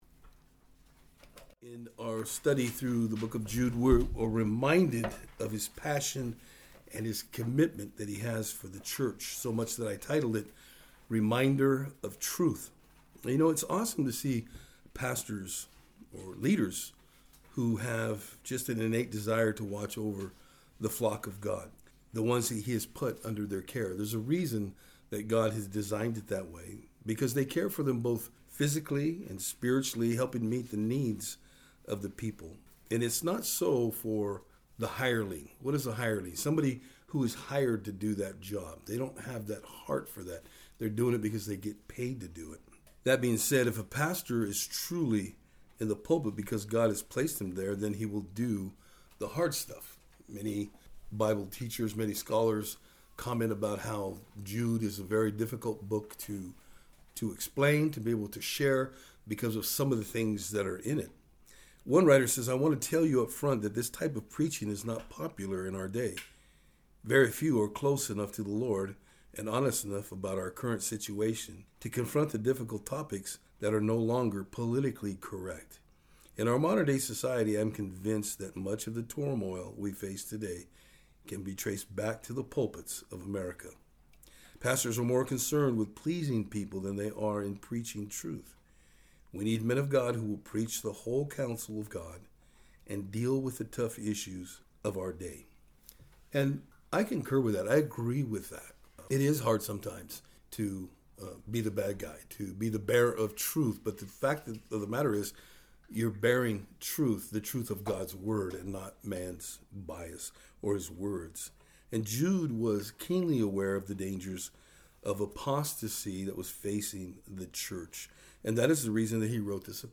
Jude 5-8 Service Type: Thursday Afternoon Today as we look into Jude 5-8 we will see just how the apostates begin to work in the church.